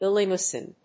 /ìlənˈɔɪən(米国英語)/